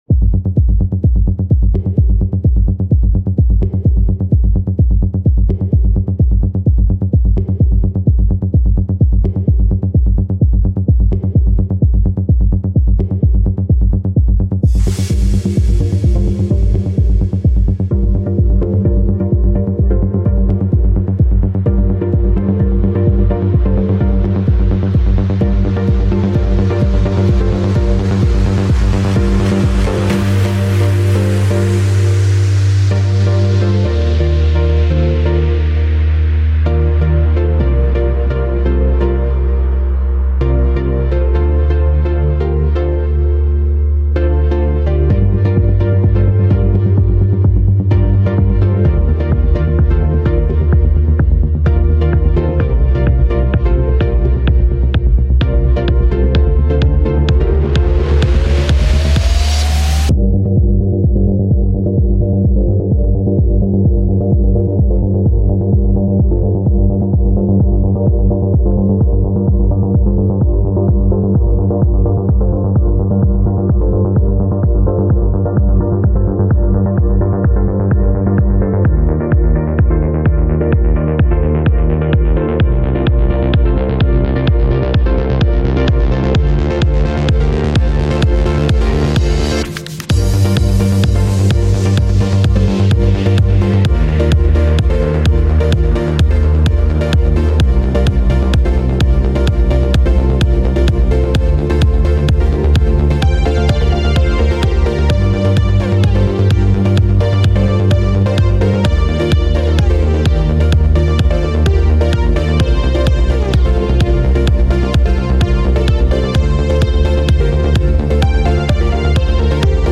[Bpm] - 128 [Genre] - Progressive House/Disco
genre:progressive house